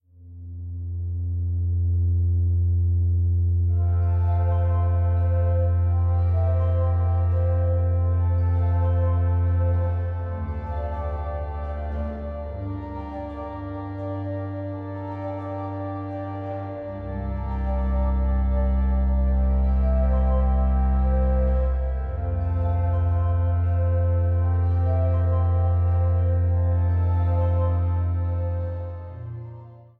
Ladegast-Orgel im Dom zu Schwerin